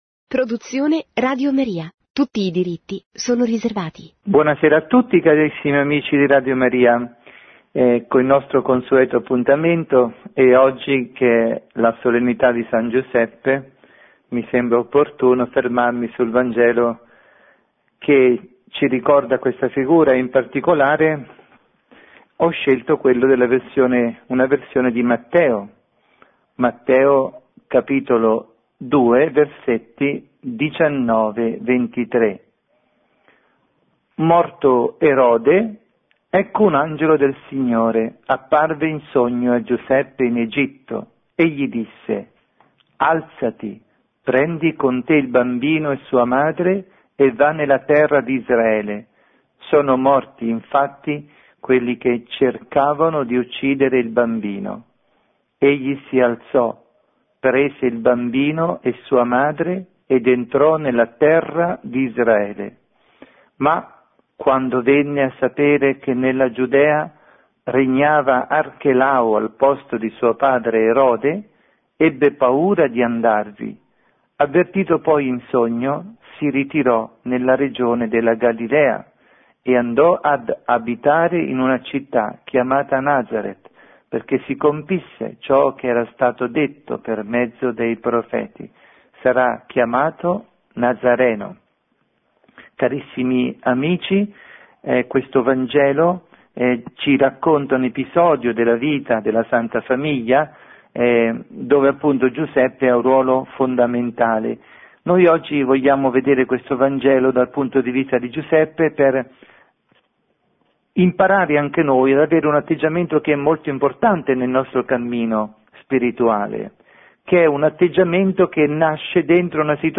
Catechesi